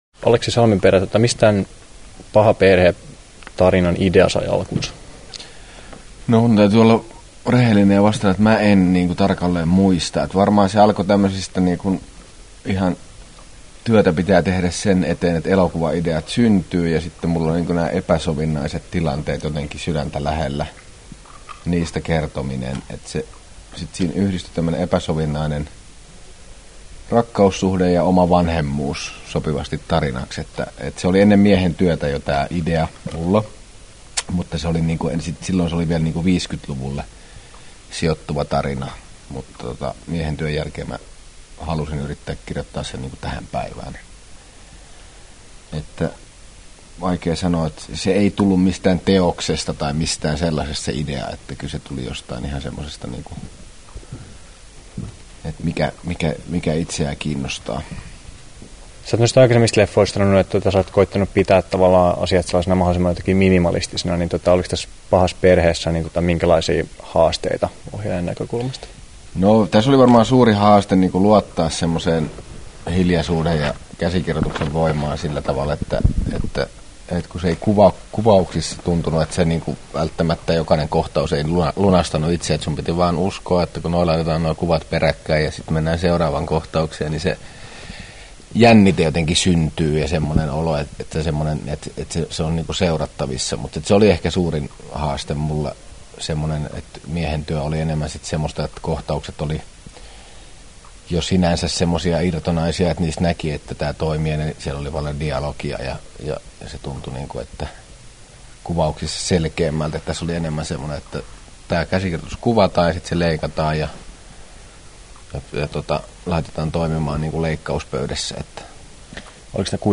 Aleksi Salmenperän haastattelu Kesto: 6’55” Tallennettu: 26.1.2010, Turku Toimittaja